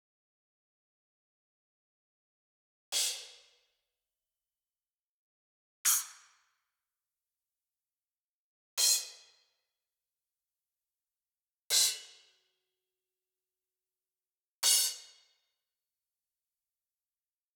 Акцентированный удар по тарелке с последующим заглушением рукой
Как выяснилось - этот исполнительский прием называется CHOKE.